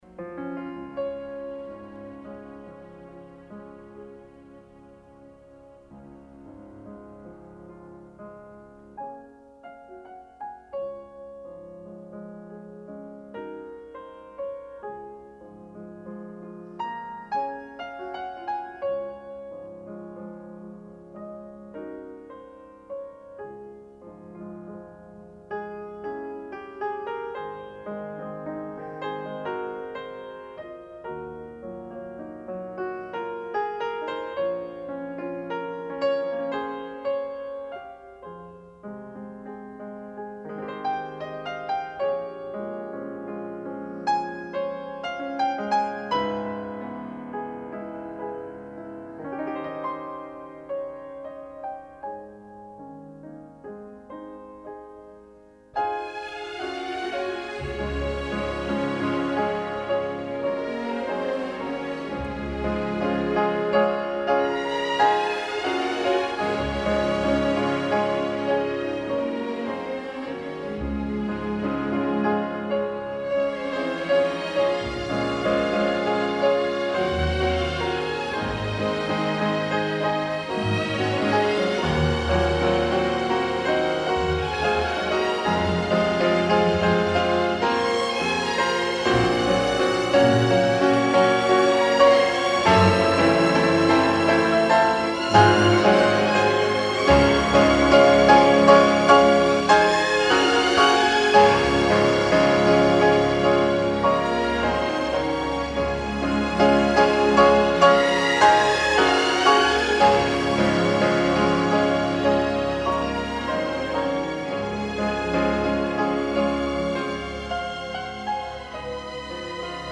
如歌的行板，钢琴加入弦乐和木管。